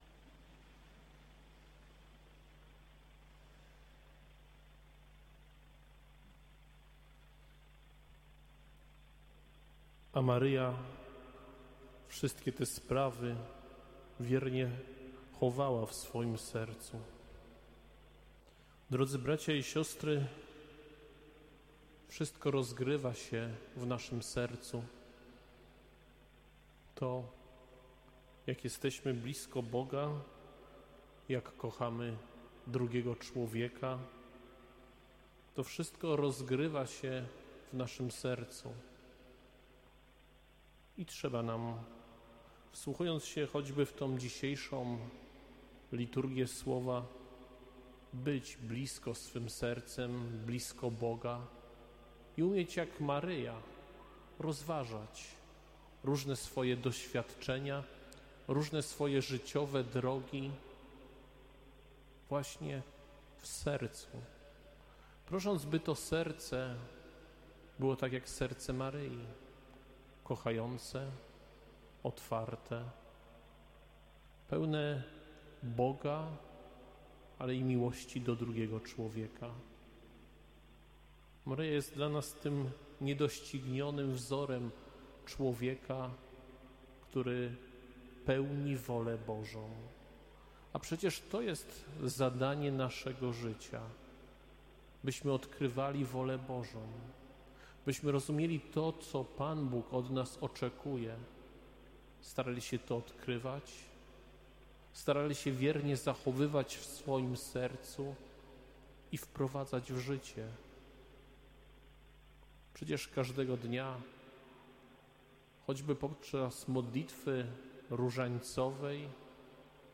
Kazanie
kazanie-1.mp3